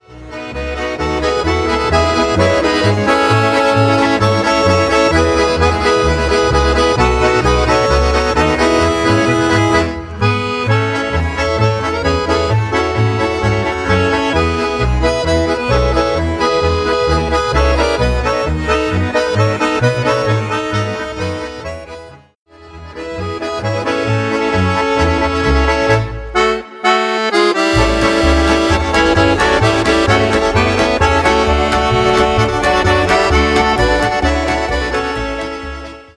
Schnellpolka